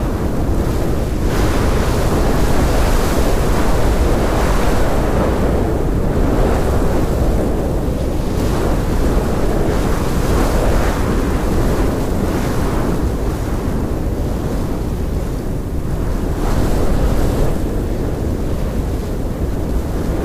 Wind4.ogg